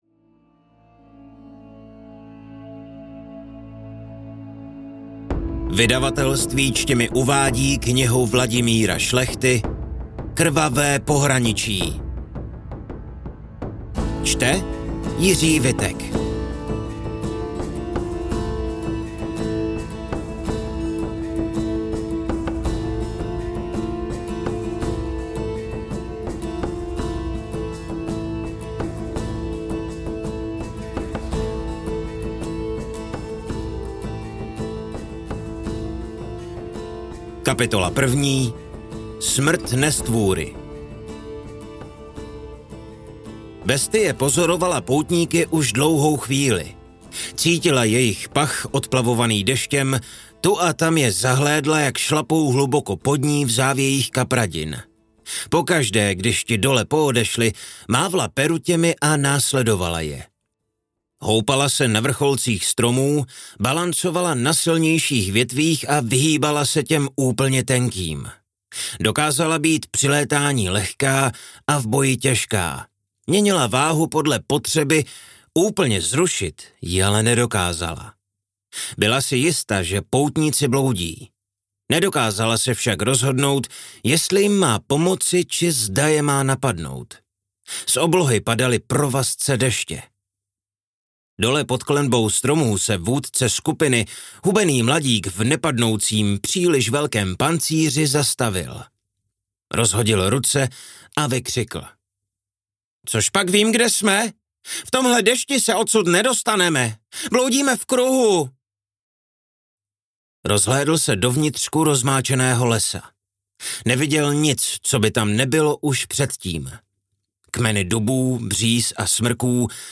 Krvavé pohraničí audiokniha
Ukázka z knihy